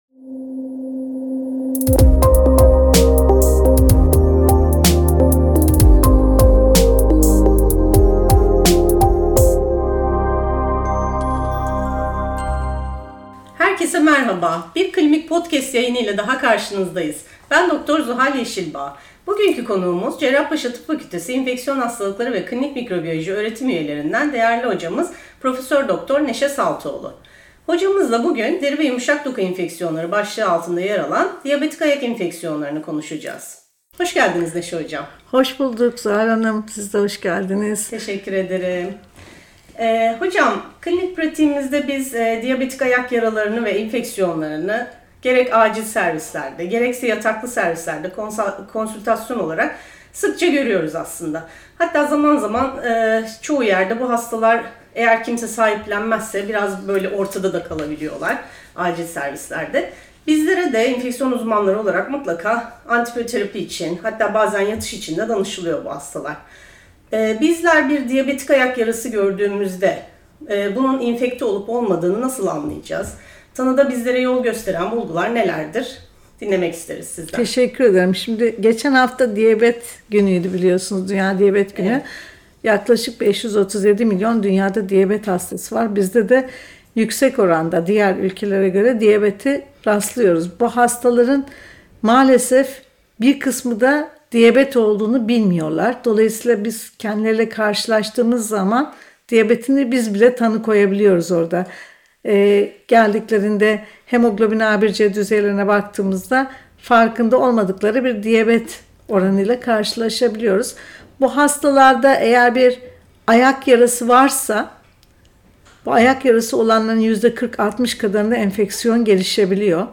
Bu yayınımızda hocamıza diyabetik ayak infeksiyonları ile ilgili merak ettiğimiz ve klinik pratiğimizde karşımıza çıkan soruları sorduk.